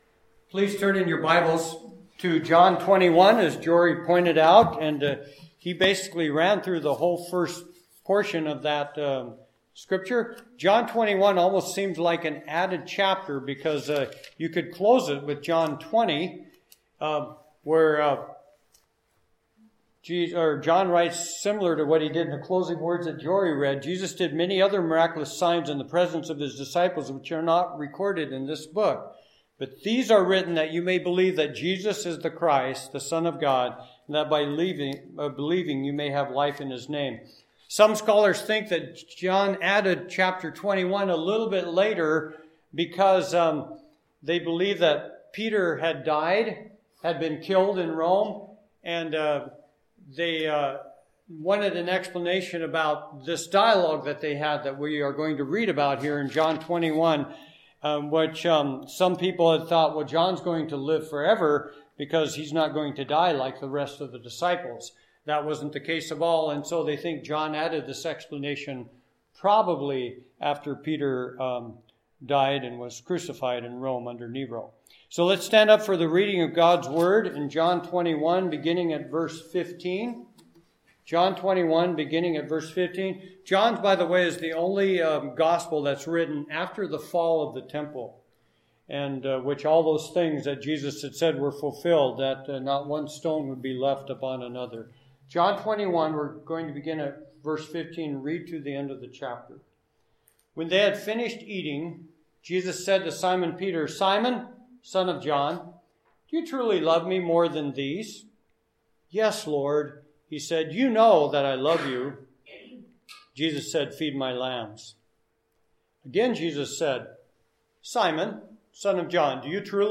Passage: John 21:18-21 Service Type: Sunday Morning